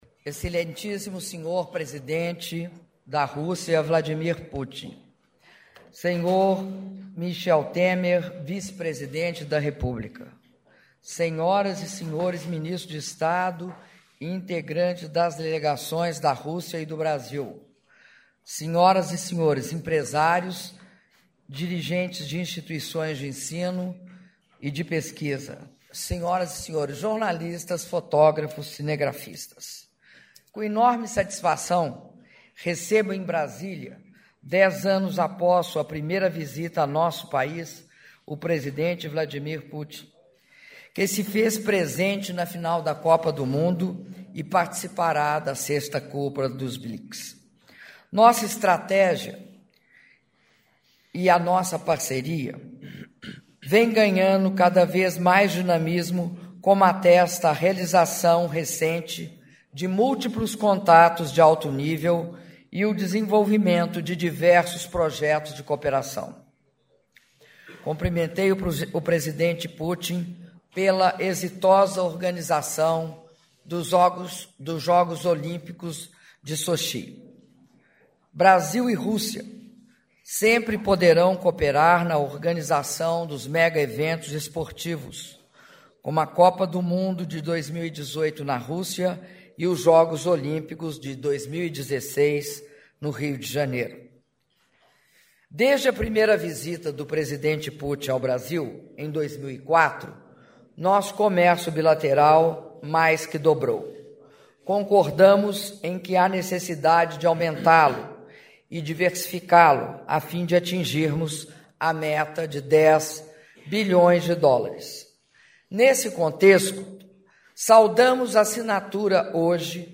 Áudio da declaração à imprensa da Presidenta da República, Dilma Rousseff, após reunião bilateral com o presidente da Rússia, Vladimir Putin - Brasília/DF (08min32s)